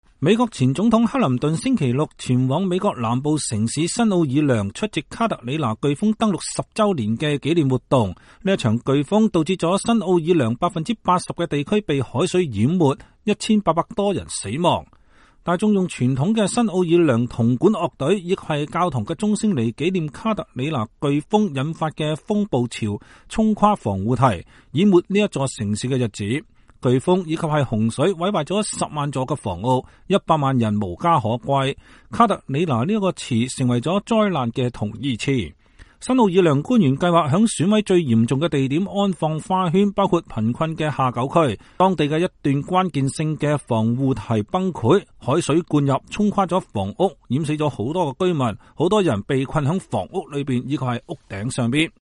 人們用傳統的新奧爾良銅管樂隊和教堂鐘聲來紀念卡特里娜颶風引發的風暴潮衝垮防護堤，淹沒這座城市的日子。